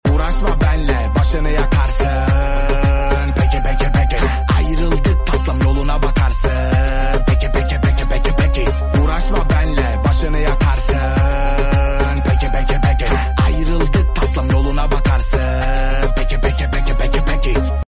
Ortama Cıvıl cıvıl Enerji katan sound effects free download